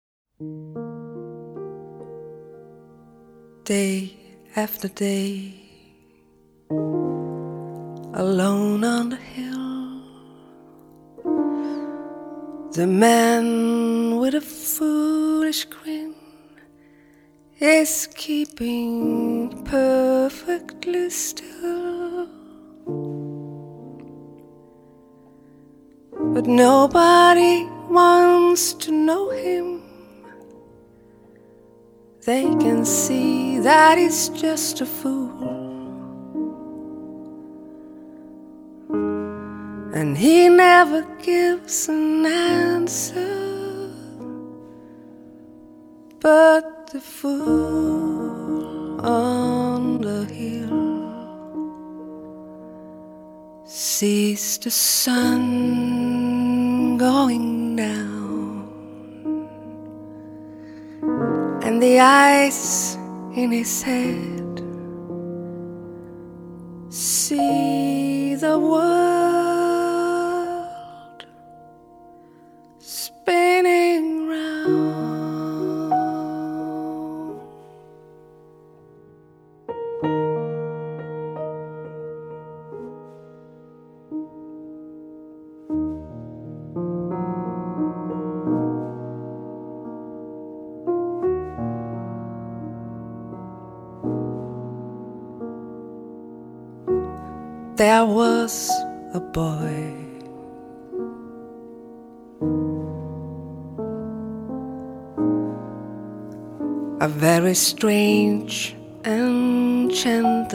★ 來自北國挪威的清澈天籟，恬靜愜意又不失動人情感的癒療系爵士／流行天后！
★ 充滿通透感、清晰而明亮又不失夢幻感的圓潤嗓音，帶您體驗人聲最美的境地！